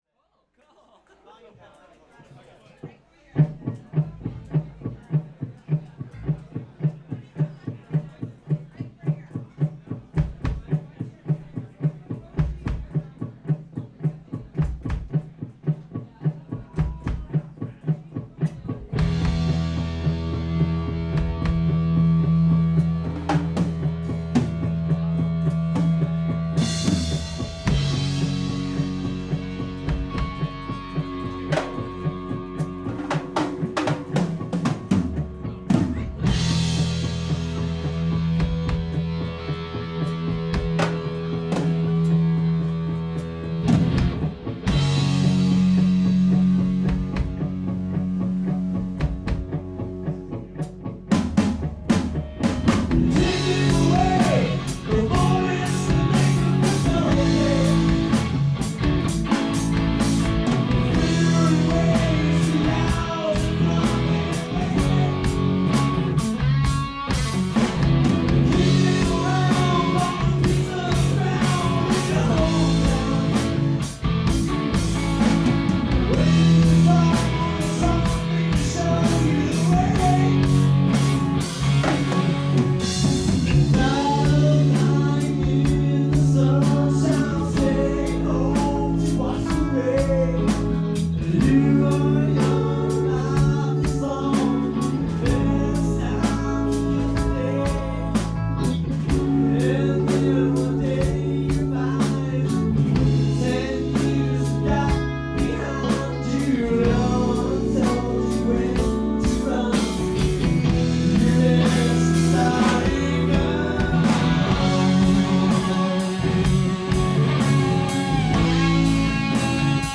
Recorded at the Creekside Tavern, Santa Barbara in 2004.
The solo is close to the original.